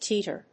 音節tee・ter 発音記号・読み方
/tíːṭɚ(米国英語), tíːtə(英国英語)/